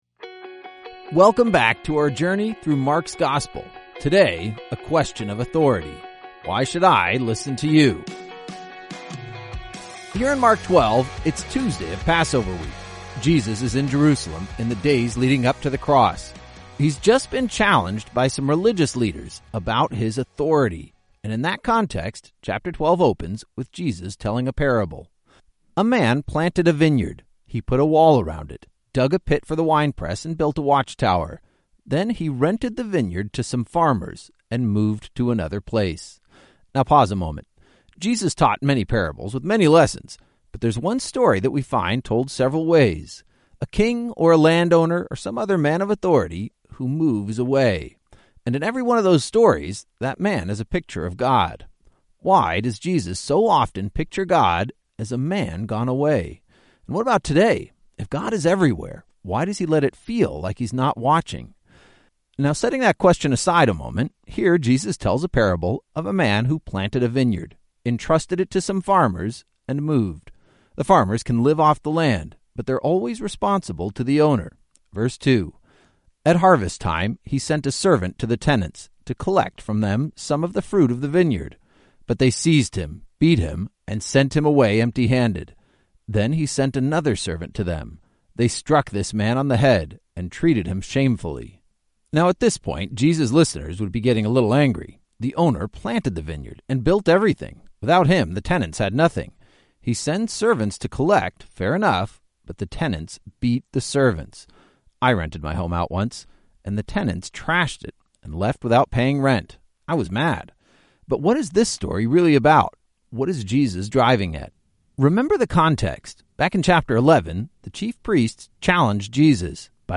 Each journey is an epic adventure through several Bible books, as your favorite pastors explain each chapter in a friendly and compelling audio guide.